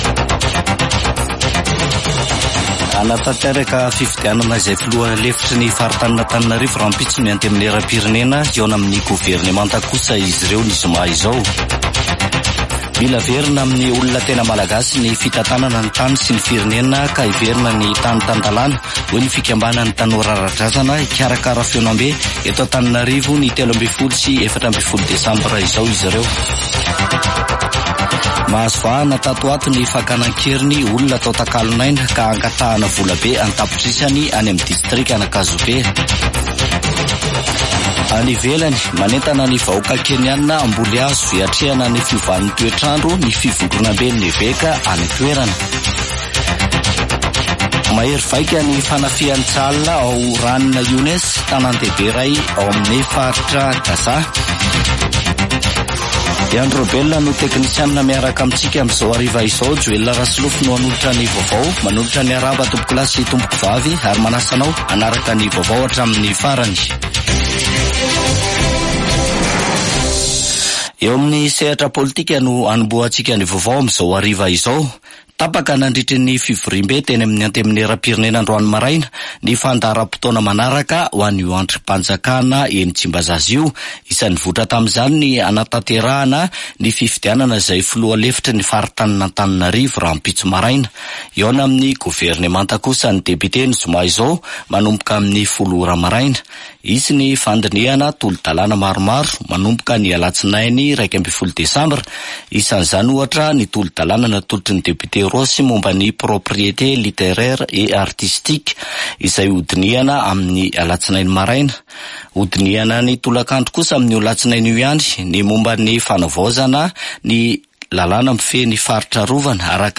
[Vaovao hariva] Alarobia 6 desambra 2023